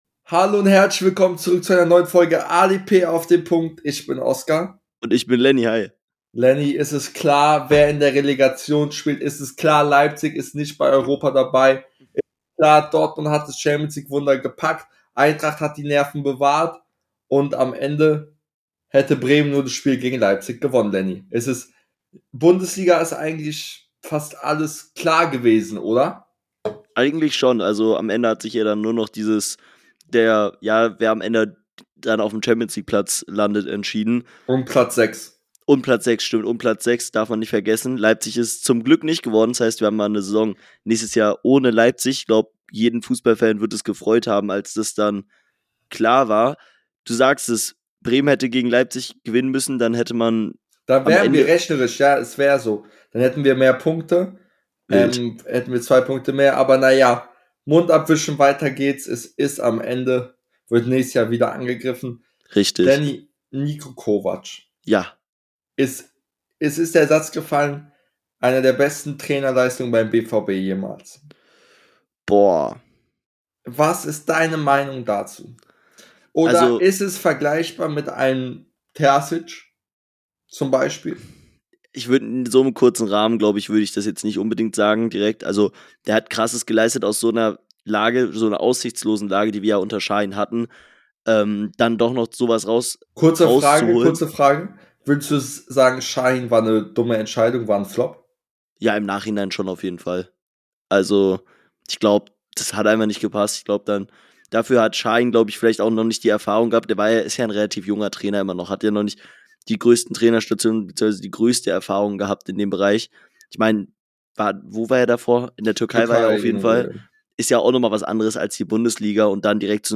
Die Entscheidungen um die Europaplätze , Aufstieg und Abstiegsplätze und viele mehr sind gefallen . Die beiden Hosts blicken auf die Relegation und das bevorstehende DFB Pokalfinale